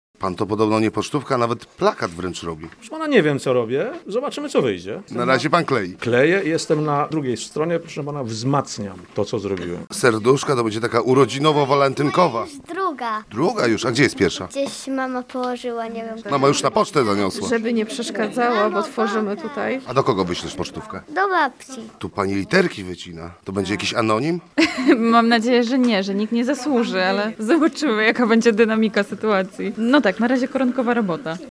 Uczestnicy zajmowali się tworzeniem kartek pocztowych, a na miejscu z mikrofonem był nasz reporter.